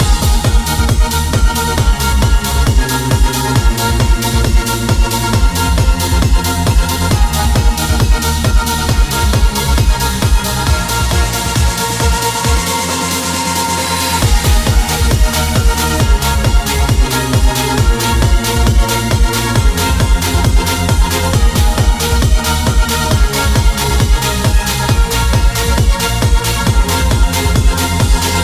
Genere: pop,dance,deep,disco,house.hit